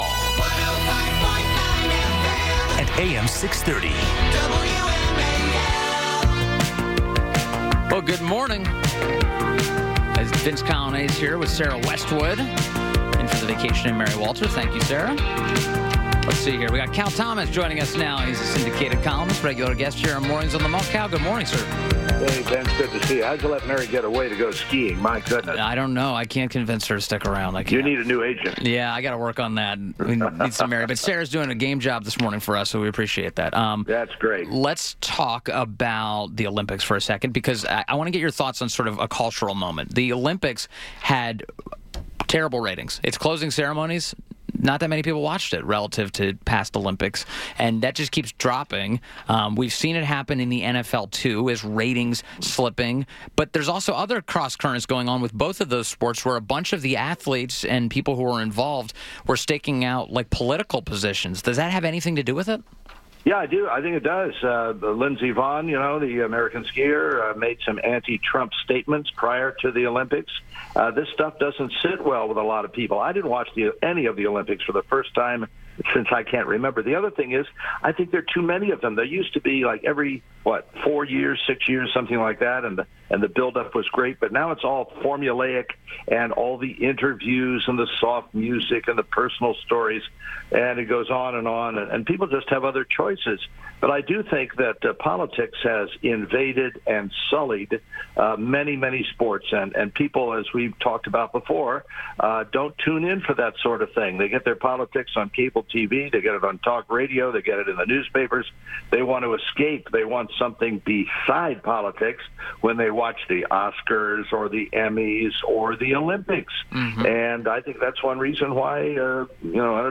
WMAL Interview - CAL THOMAS - 02.28.18
INTERVIEW - CAL THOMAS - syndicated columnist – discussed the terrible ratings for the Olympics, Jimmy Kimmel hosting the Oscars, and the gun control debate